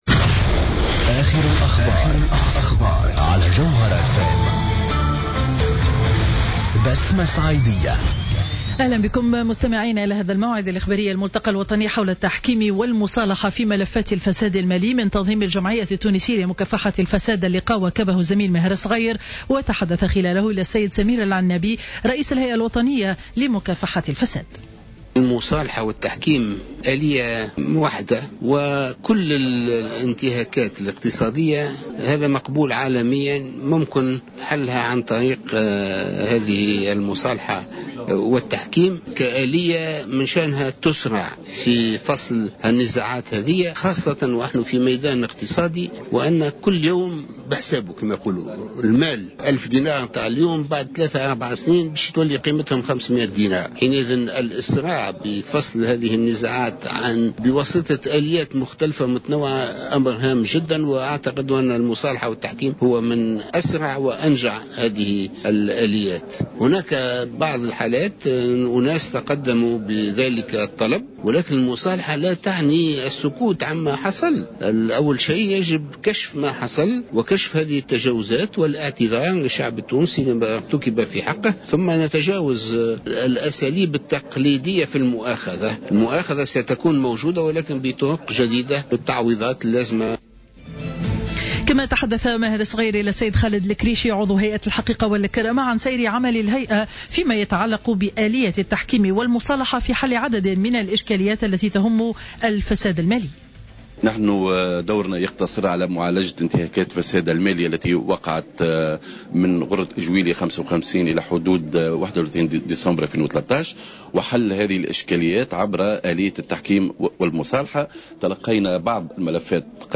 نشرة أخبار منتصف النهار ليوم الجمعة 24 أفريل 2015